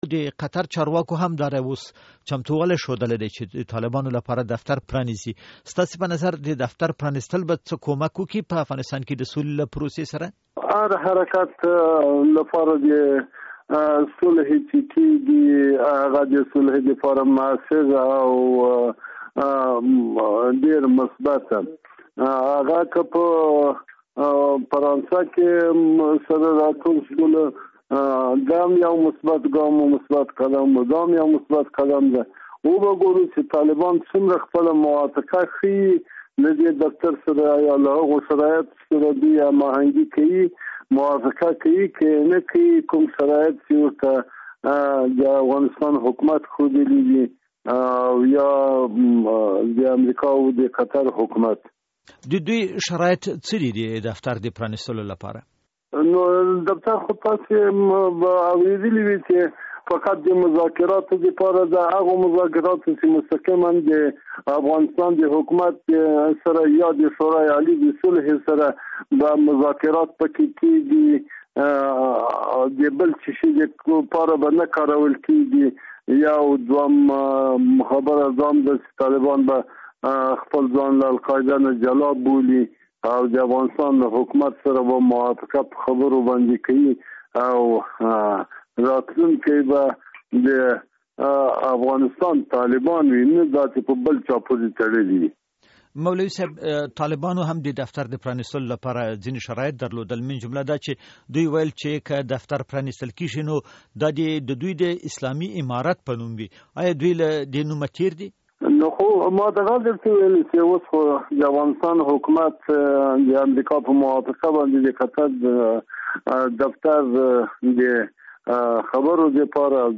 له مولوي قلم الدین سره مرکه